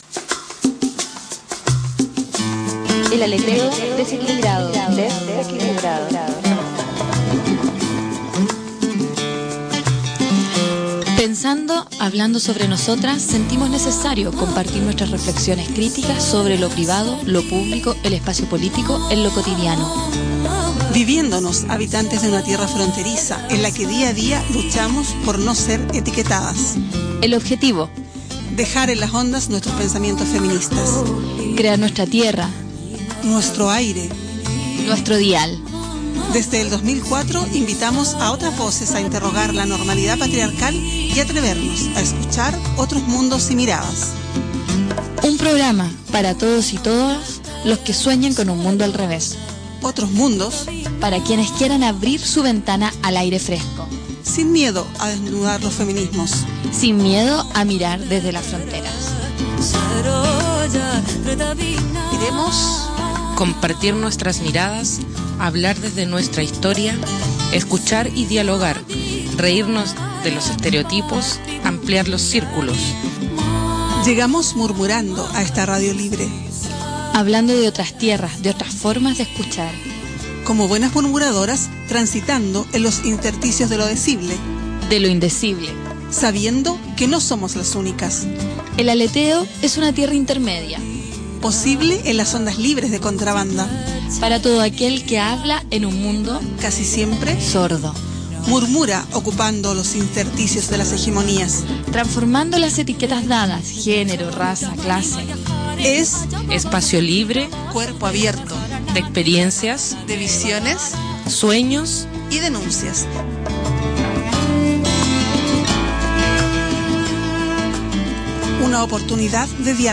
Ponemos música colombiana, anunciamos el próximo escrache feminista del día 16 y mucho más.